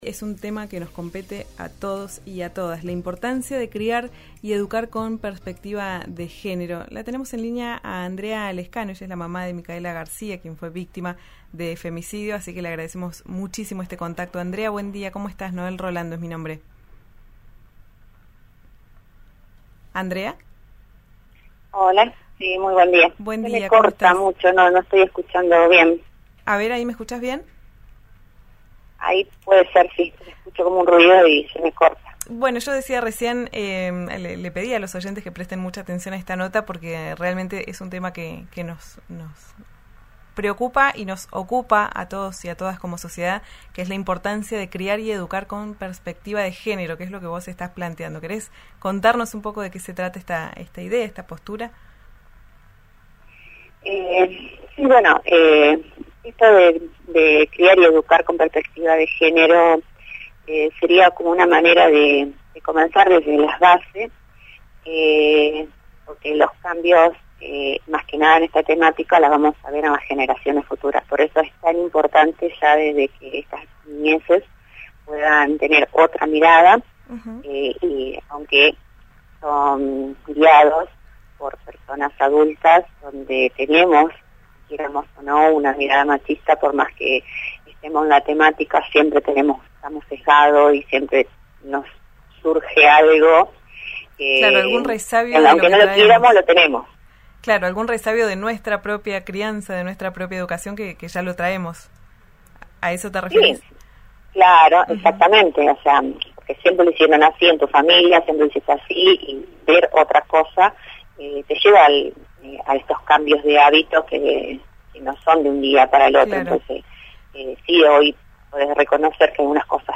en comunicación con «Ya es tiempo» por RÍO NEGRO RADIO: